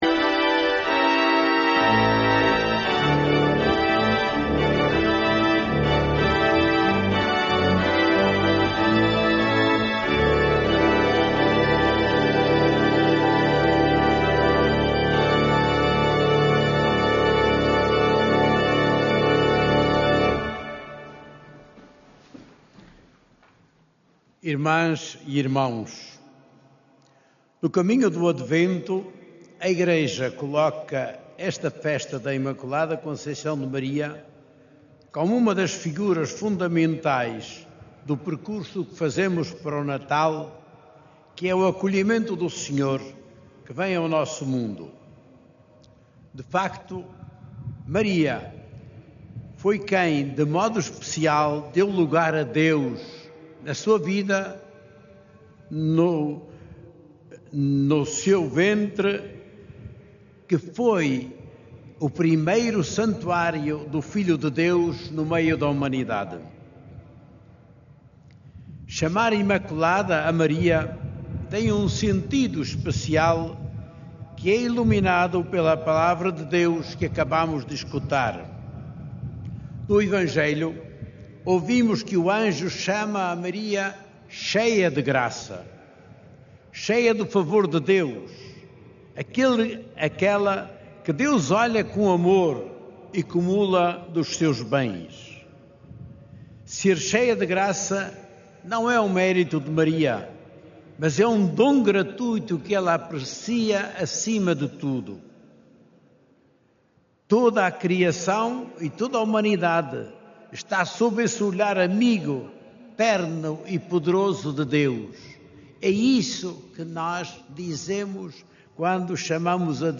O bispo de Leiria-Fátima, D. José Ornelas, presidiu à missa celebrada, esta manhã, no Recinto de Oração do Santuário de Fátima, e destacou a vocação da Imaculada Conceição como resposta ao projeto divino.